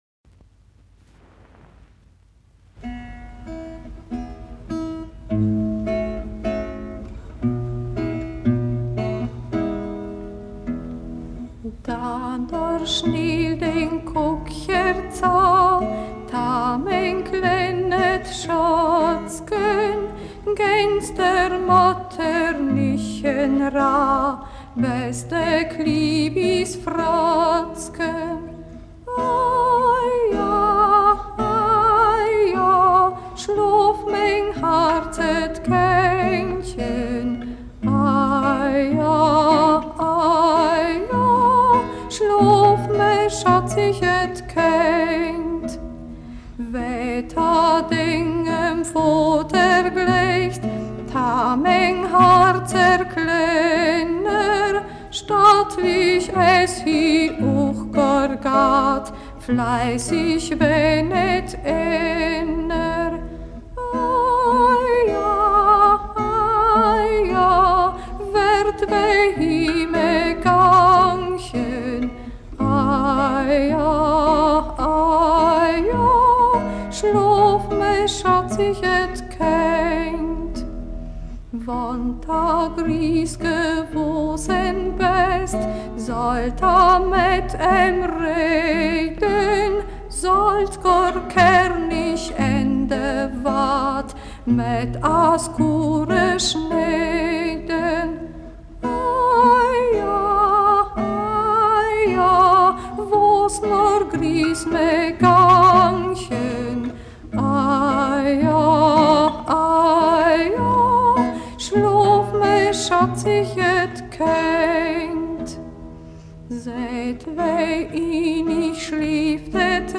Ortsmundart: Schäßburg